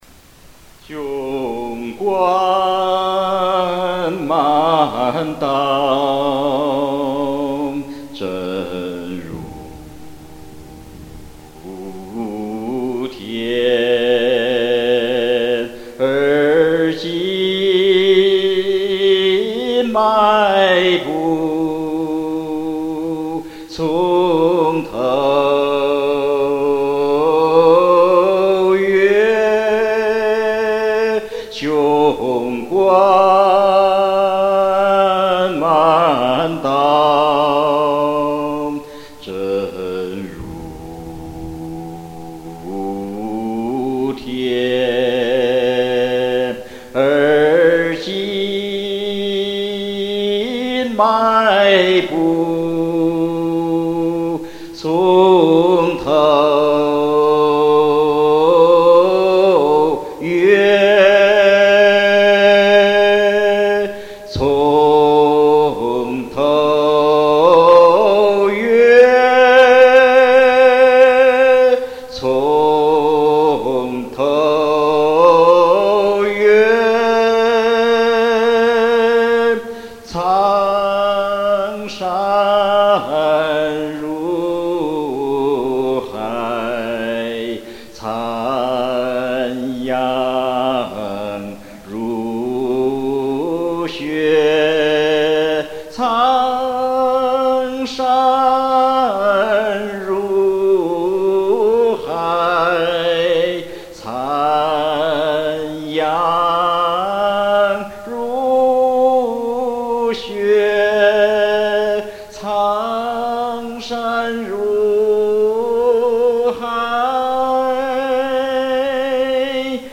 厉害，雄壮豪迈！